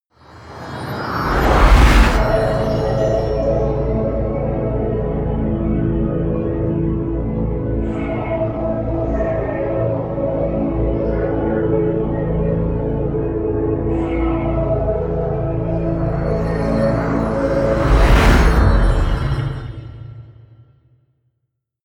Gemafreie Musikelemente: Drones